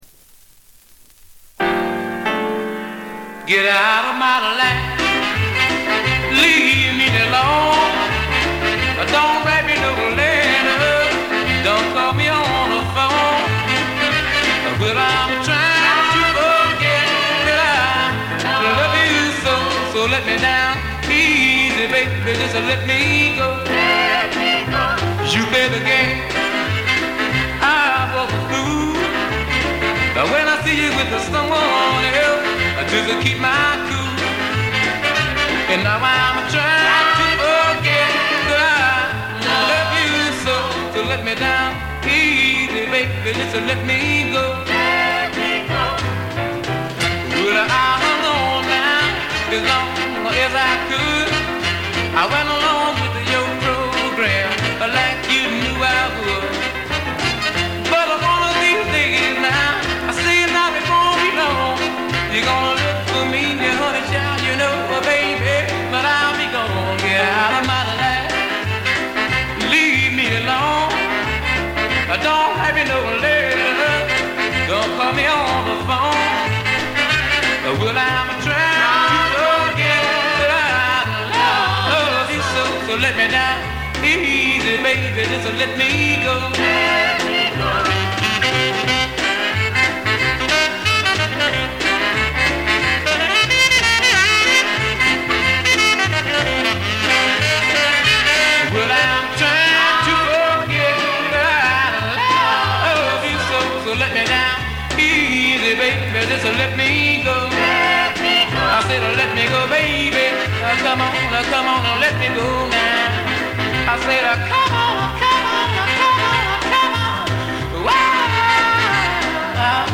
静音部で微細なバックグラウンドノイズが聴かれる程度。
モノラル盤。
試聴曲は現品からの取り込み音源です。